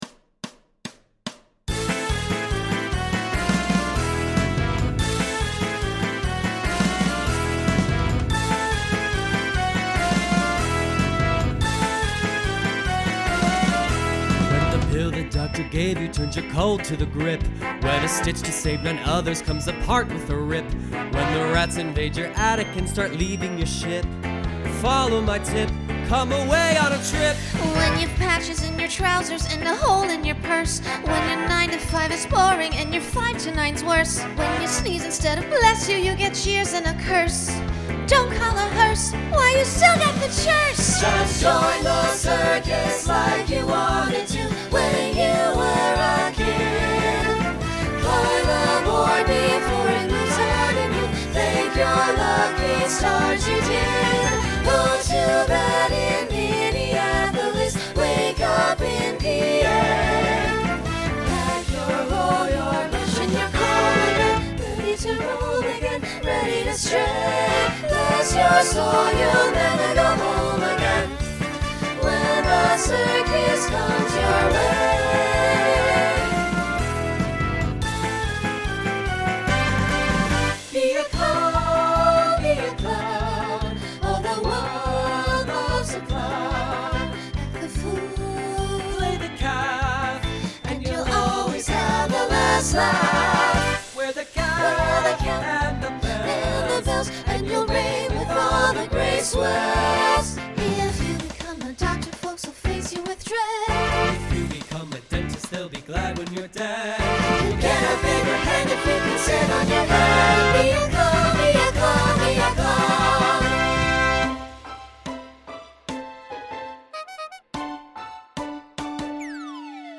Genre Broadway/Film Instrumental combo
Novelty Voicing SATB